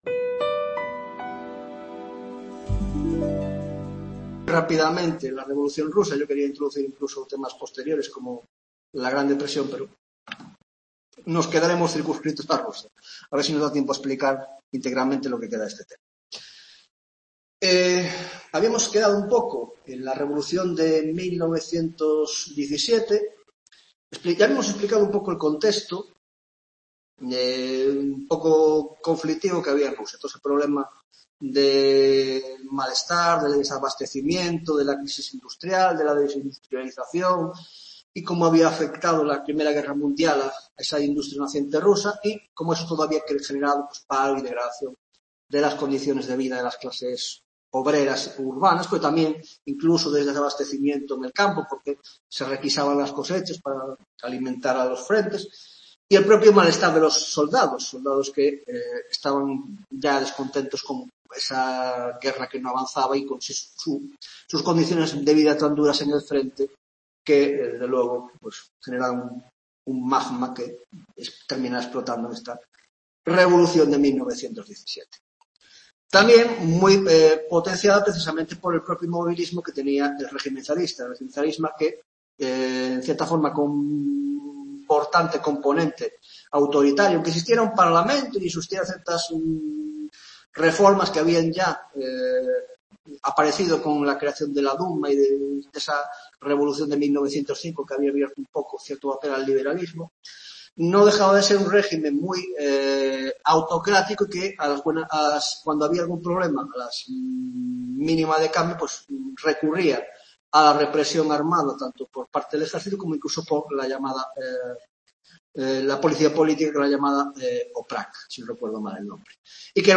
15ª tutoria de Historia Contemporánea - La Revolución Rusa (1917) (1ª parte)